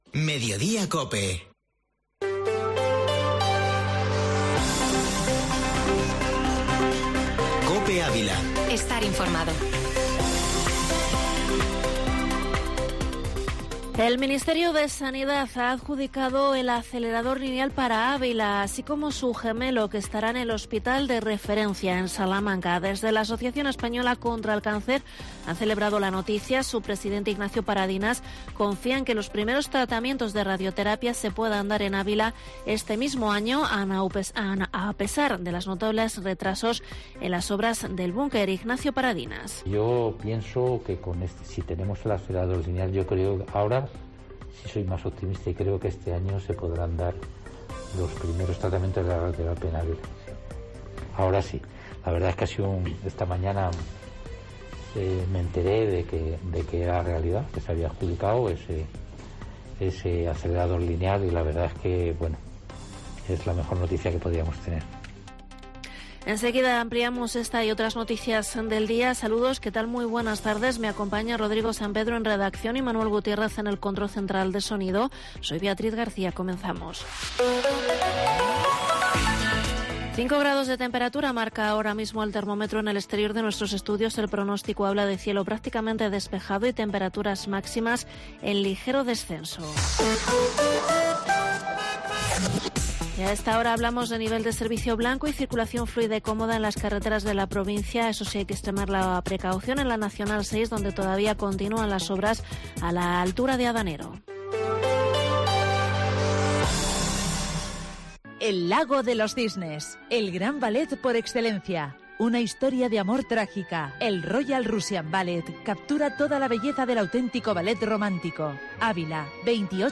Informativo Mediodía Cope en Avila 24/1/2022